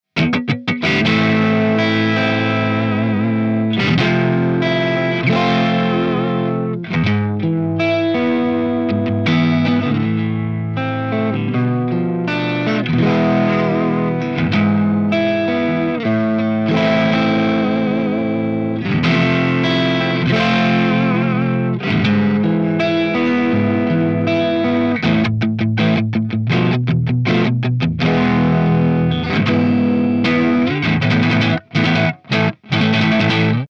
Chords
RAW AUDIO CLIPS ONLY, NO POST-PROCESSING EFFECTS